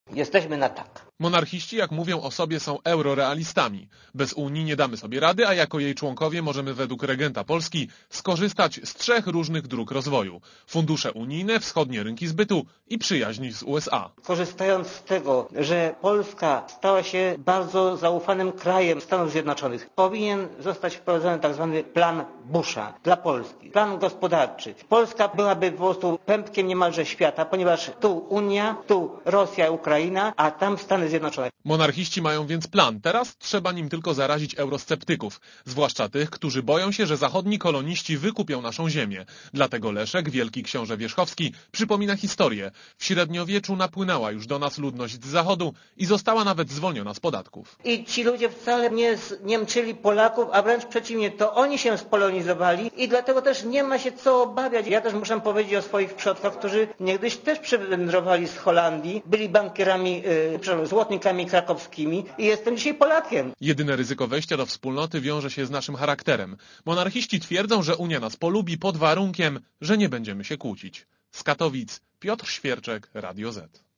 Relacja reportera Radia Zet (290Kb)